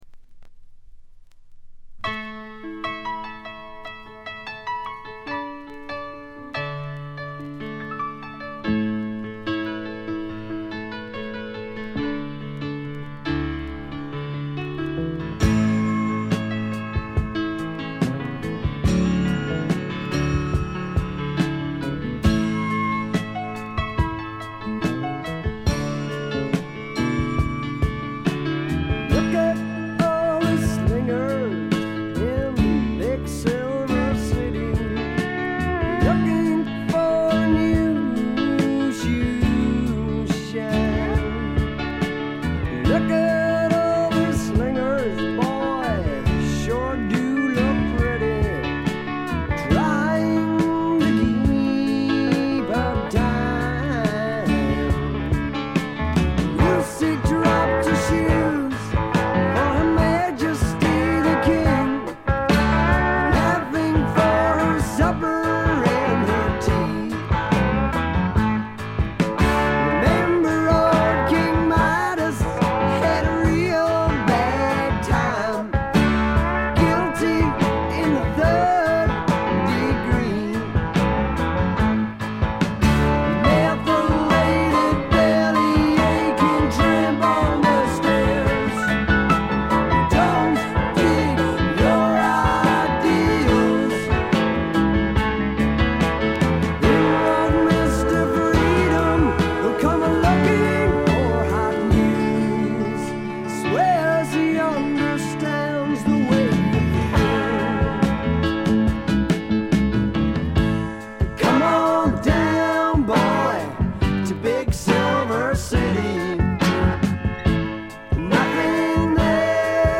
*** LP ： UK 1974
試聴曲は現品からの取り込み音源です。